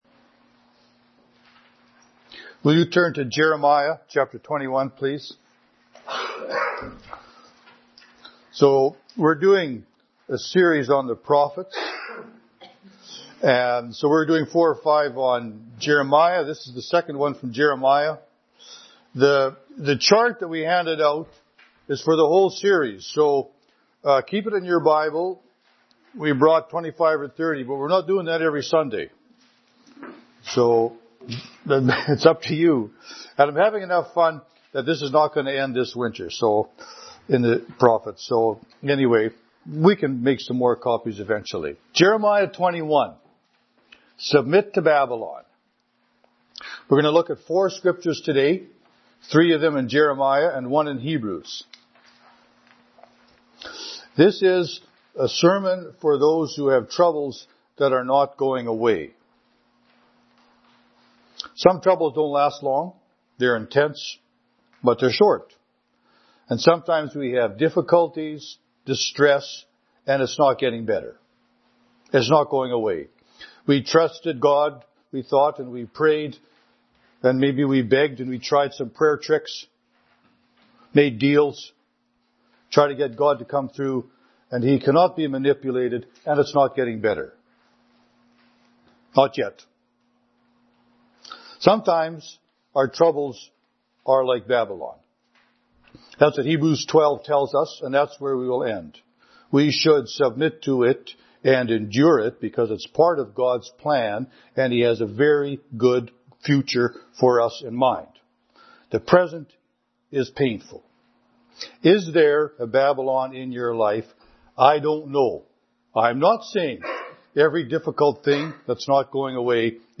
This is a sermon for those who have troubles that are not going away.